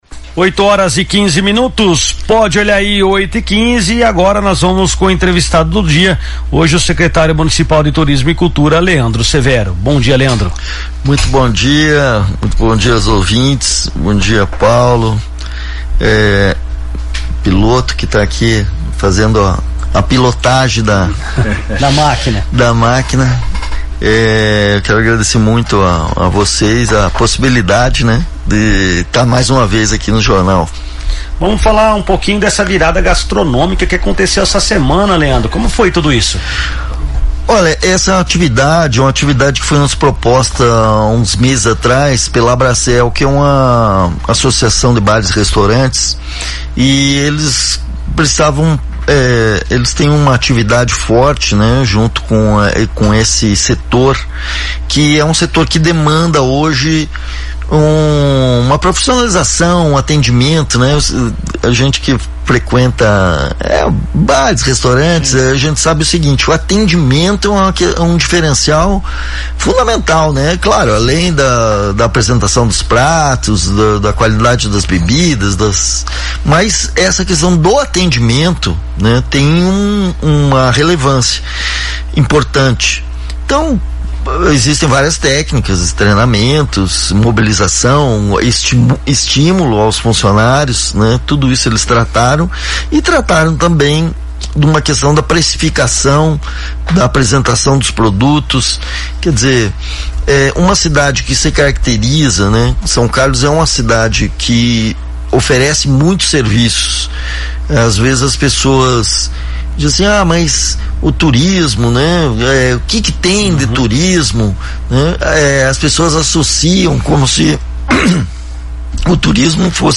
São Carlos fortalece turismo e amplia oferta cultural, afirma secretário em entrevista à São Carlos FM |
O secretário municipal de Turismo e Cultura de São Carlos, Leandro Severo, foi o entrevistado desta quinta-feira (13) no programa “Primeira Página no Ar”, da São Carlos FM (107,9), e apresentou um amplo diagnóstico sobre o desenvolvimento turístico e cultural do município. Durante a entrevista, Severo destacou iniciativas estruturantes, ações de qualificação profissional, avanços na relação com o setor privado e o andamento do processo para que o município receba o título de Município de Interesse Turístico (MIT).